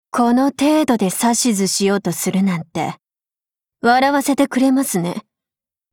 贡献 ） 协议：Copyright，人物： 碧蓝航线:格奈森瑙·META语音 您不可以覆盖此文件。